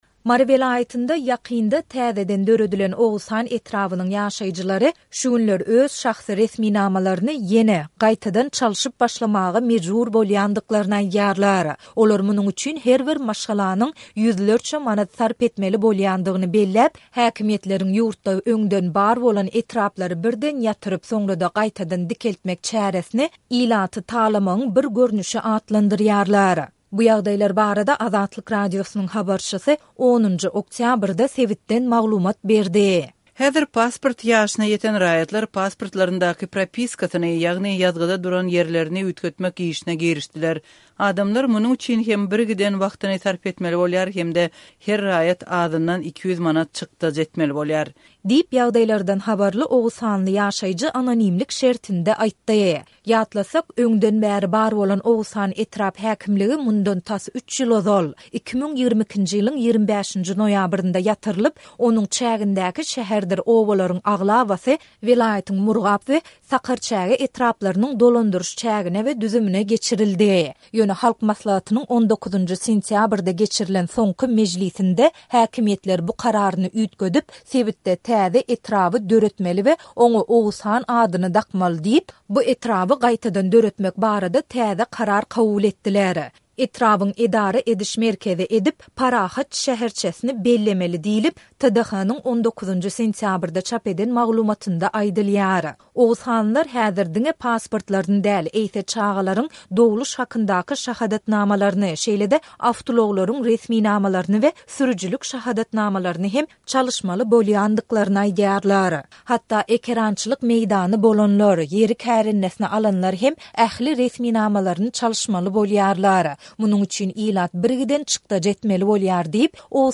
Mary welaýatynda ýakynda täzeden döredilen Oguzhan etrabynyň ýaşaýjylary şu günler öz şahsy resminamalaryny ýene çalşyp başlamaga mejbur bolýandyklaryny aýdýarlar. Bu ýagdaýlar barada Azatlyk Radiosynyň habarçysy 10-njy oktýabrda sebitden maglumat berdi.